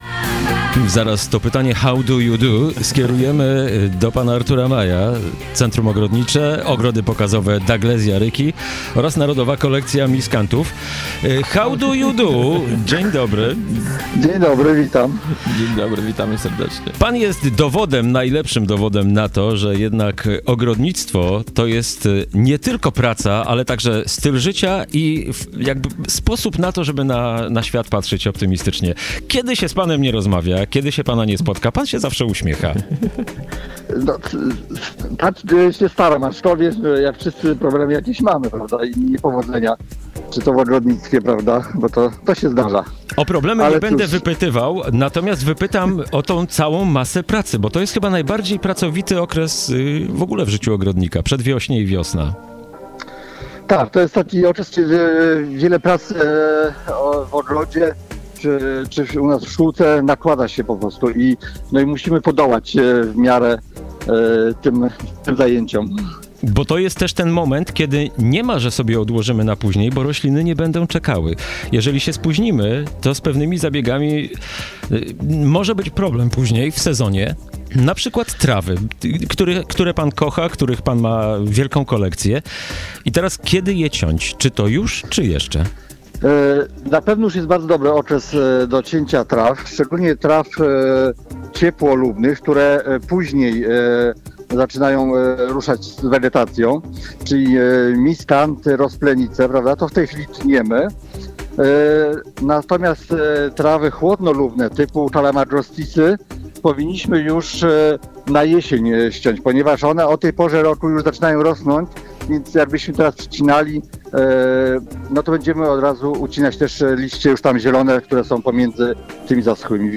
Temat ten poruszymy już w najbliższych audycjach, a tymczasem zapraszamy do wysłuchania rozmowy o miskantach: